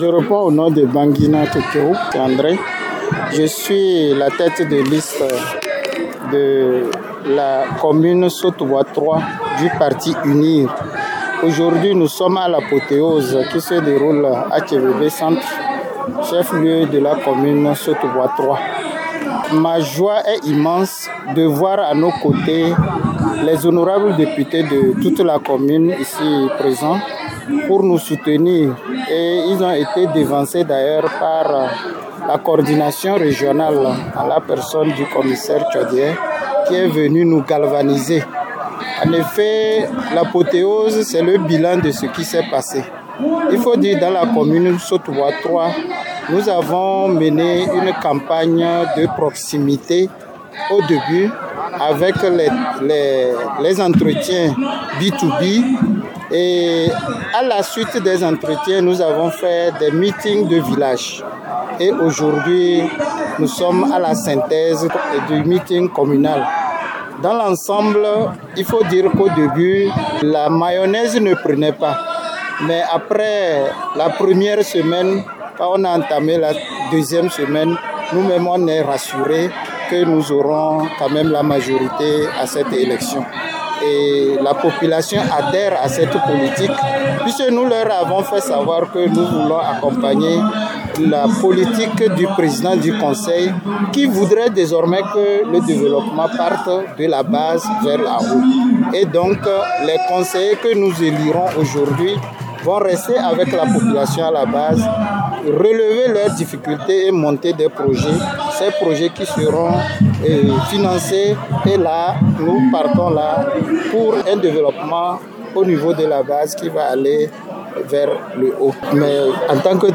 Le parti Union pour la République (UNIR) a mobilisé une foule massive à Tchebebe (Sotouboua 3) le 14 juillet pour son meeting apothéose avant les municipales.